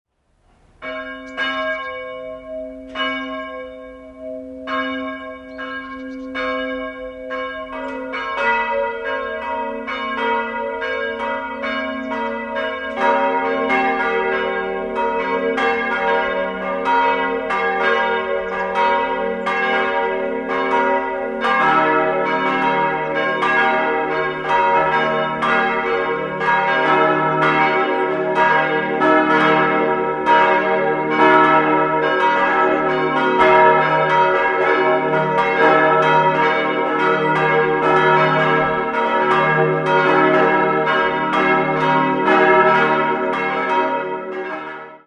Am Ortsrand von Sollern erhebt sich die für das kleine Dorf sehr stattliche Pfarrkirche, die in den Jahren 1689 bis 1717 erbaut wurde und 1735 ihre barocke Altarausstattung erhielt. Im Jahr 1702 wurde in Sollern eine Skapulierbruderschaft gegründet. 4-stimmiges Salve-Regina-Geläute: es'-g'-b'-c'' Die Glocken stammen aus der Gießerei Hahn in Landshut und wurden 1948 angeschafft. Die exakte Tonfolge lautet: es'+4 - g'+0 - b'+4 - c''-4.